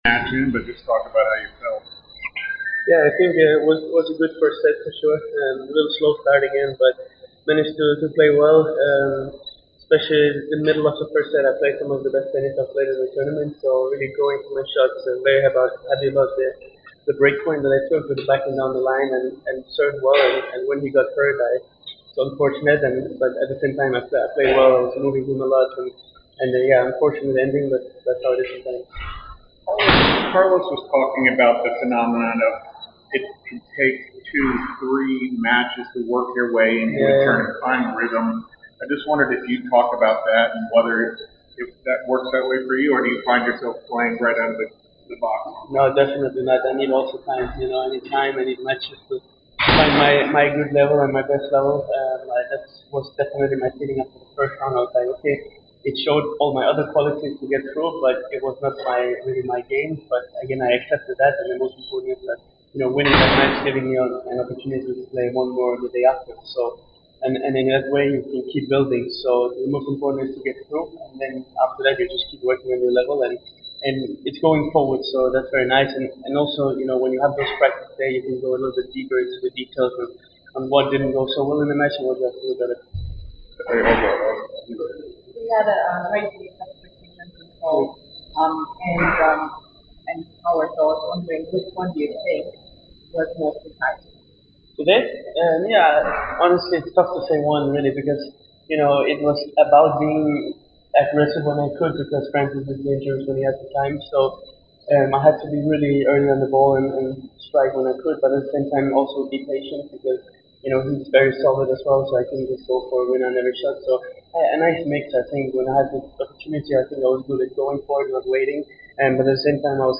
08-13-25 Holger Rune Interview
Holger Rune post-match interview after defeating Frances Tiafoe 6-4. 3-1 in the Round of 16 of the Cincinnati Open.